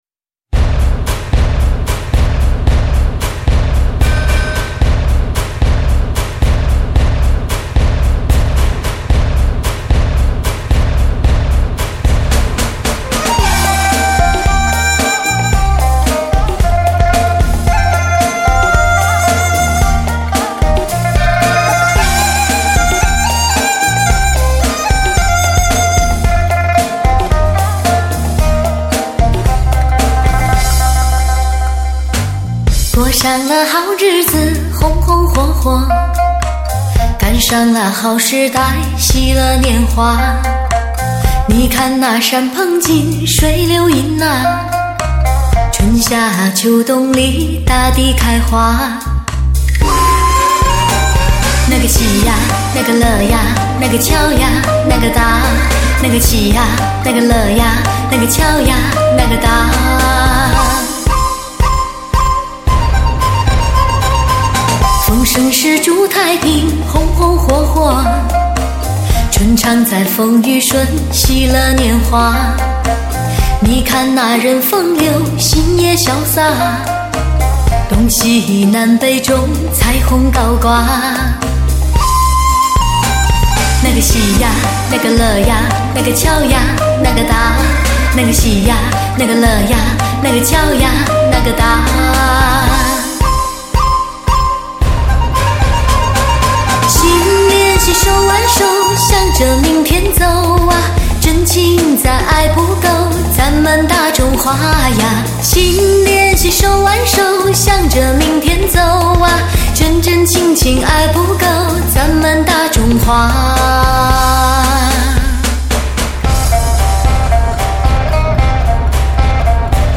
最新最火红贺岁金曲，发烧珍藏！
为低音质MP3